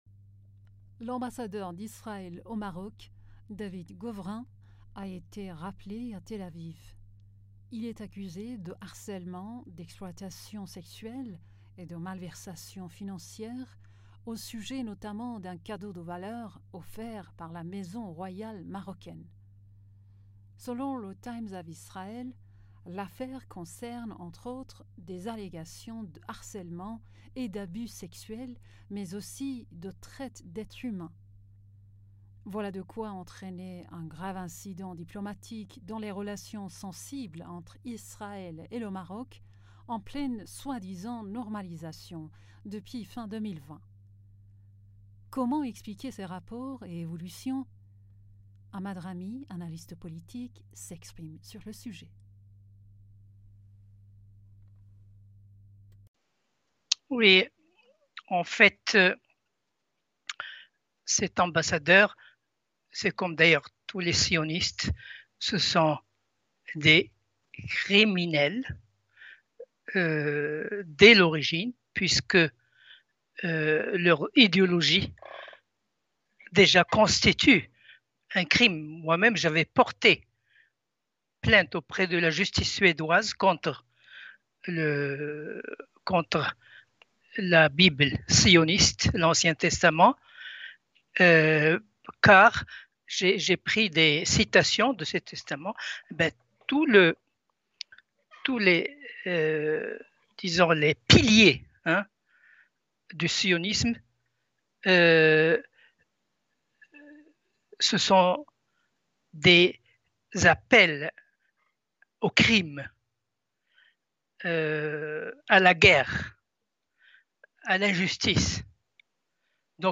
Mots clés Afrique Israël interview Eléments connexes Un Israélien tué par une panthère iranienne : récit et réaction en ligne Vague de suicides chez les soldats israéliens : le régime en pleine crise interne ?